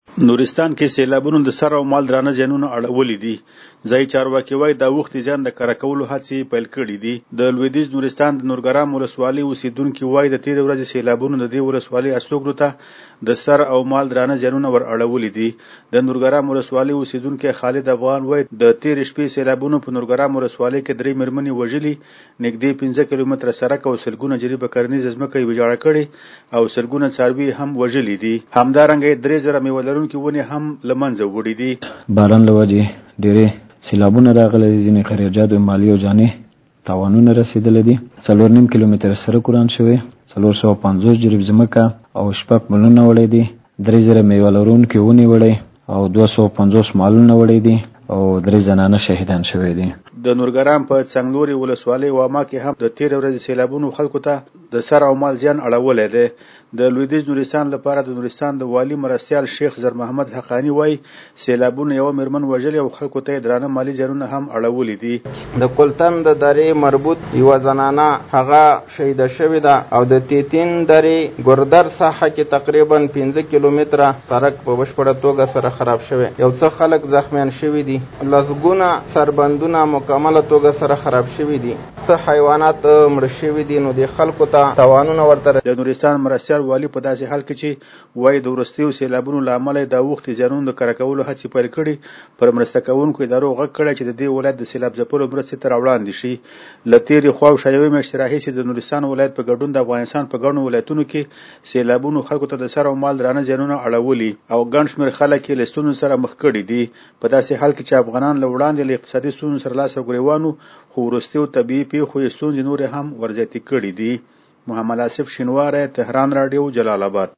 زمونږ خبریال رپورټ راکړی چې د لویدیز نورستان د نورګرام ولسوالۍ اوسیدونکي وایي دت ېرې ورځې سیلابونو ددې ولسوالۍ استوګنوته دسر او مال درانه زیانونه ور اړولي دي .